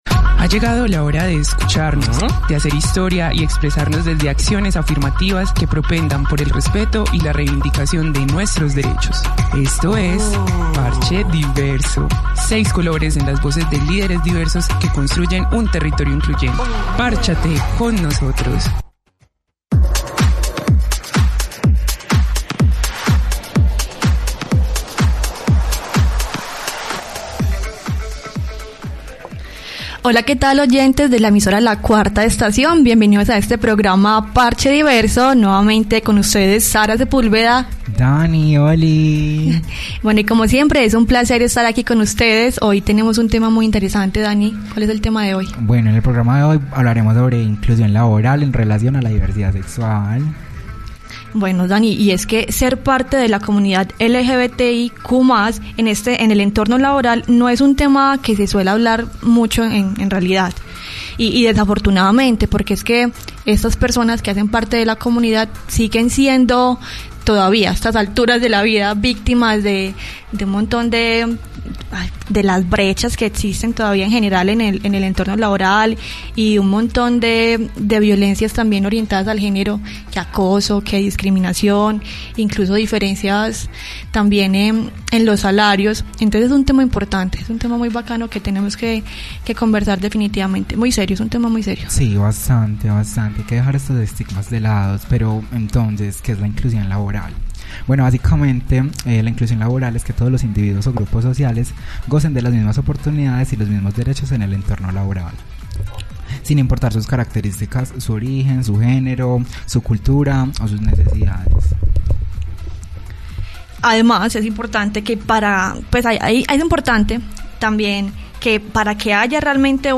En la voz de sus protagonistas, analizaremos cómo han sido los procesos y experiencia de inclusión al mercado laboral de las personas LGBTIQ+, en nuestra ciudad.